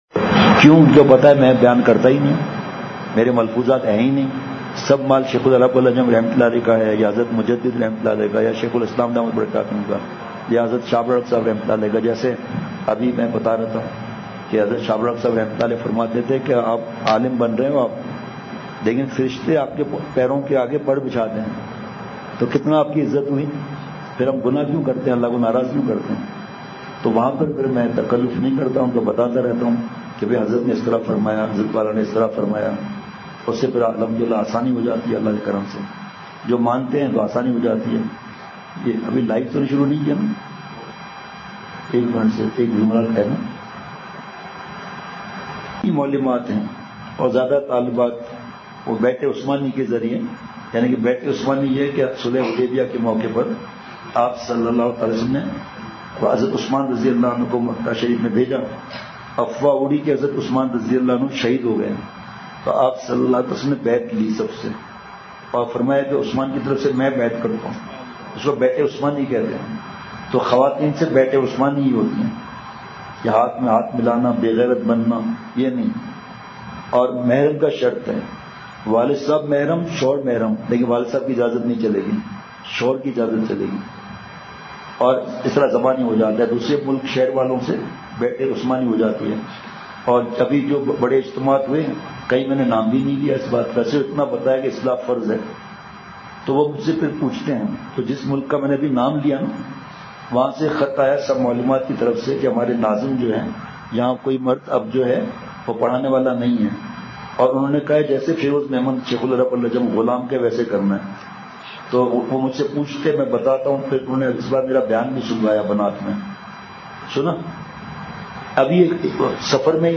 بمقام: جامعہ کنزالعلوم یارو پشین۔ بعد نماز ظہر بیان